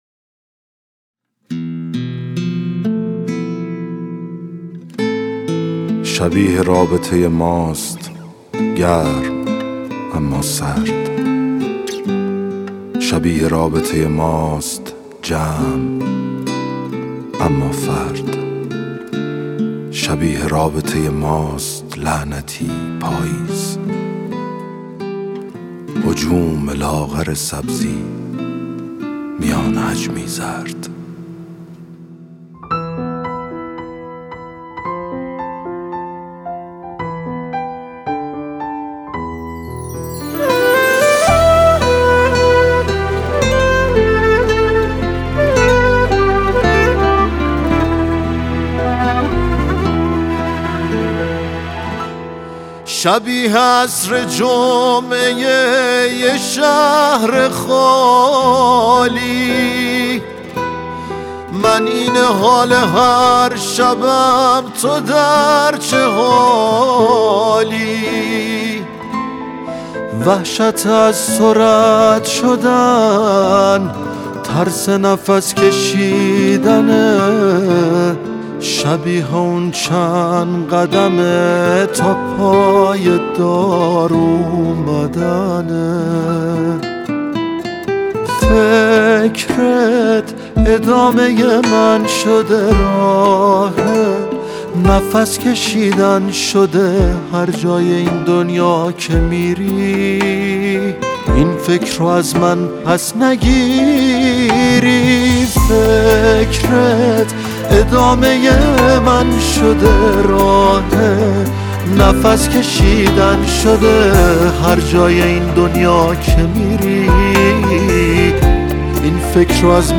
با ریتمی احساسی و متفاوت
موسیقی پاپ
این قطعه با حال و هوای احساسی و ریتمی متفاوت